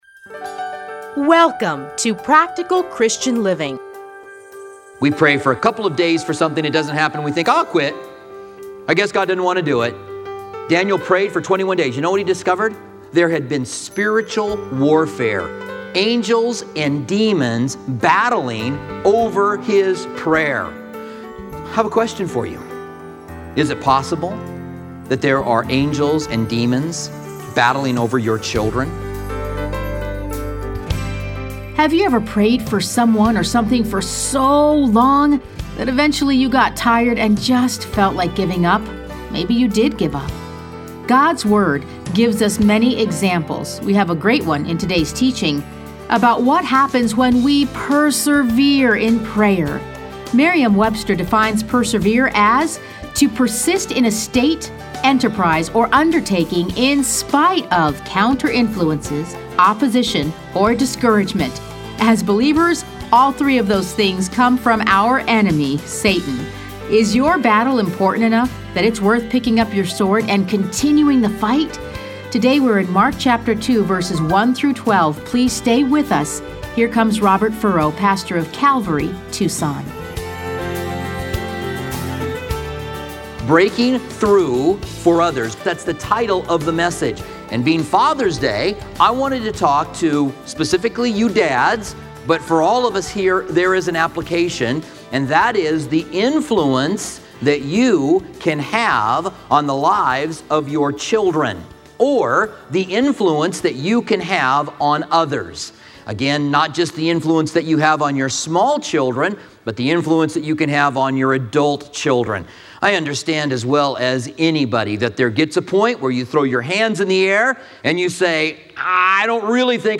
Listen to a teaching from Mark 2:1-12.